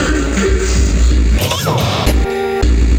80BPM RAD8-L.wav